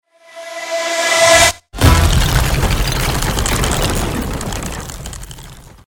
crack.mp3